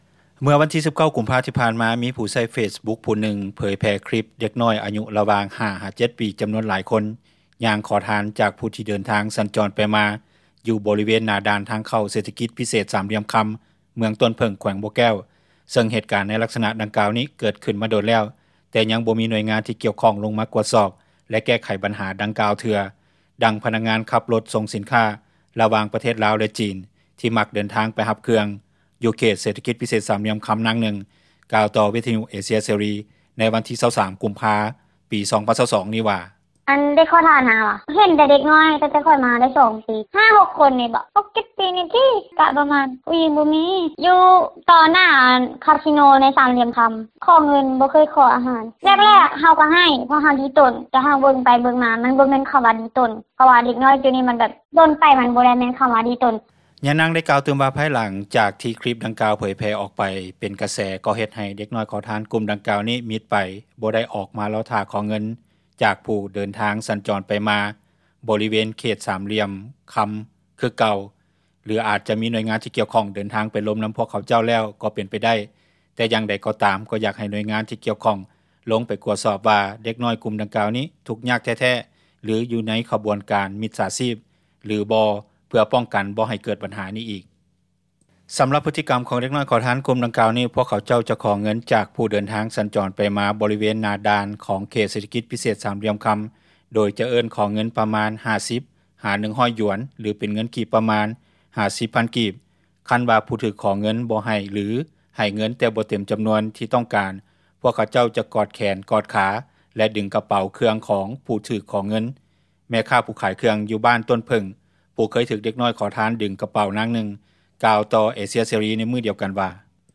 ດັ່ງຊາວບ້ານເຮັດວຽກ ແລະອາສັຍຢູ່ບໍຣິເວນອ້ອມຂ້າງເຂດເສຖກິຈ ພິເສດສາມຫລ່ຽມຄຳທ່ານນຶ່ງກ່າວ ໃນມື້ດຽວກັນວ່າ:
ດັ່ງເຈົ້າໜ້າທີ່ທີ່ກ່ຽວຂ້ອງ ຜູ້ບໍ່ປະສົງອອກຊື່ແລະຕຳແໜ່ງຢູ່ແຂວງບໍ່ແກ້ວ ທ່ານນຶ່ງກ່າວວ່າ: